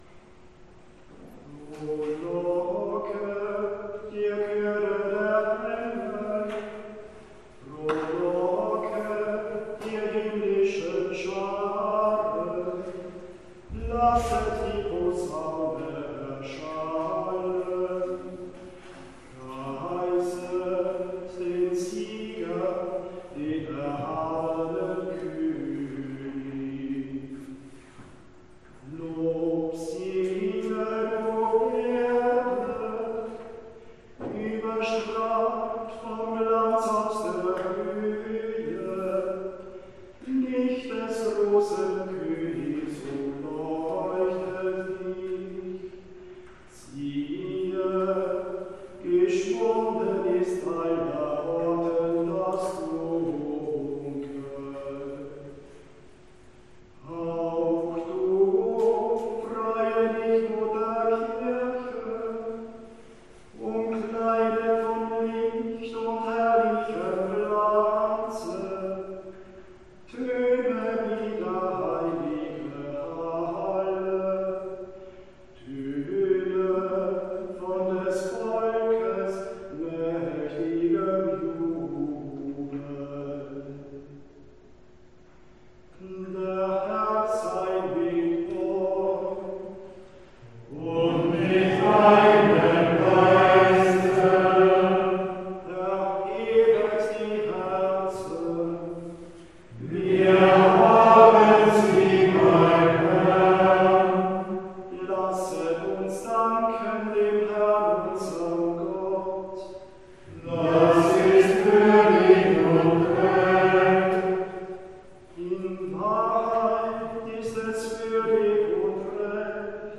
Darauf folgte das feierlich gesungene Exultet (Osterlob).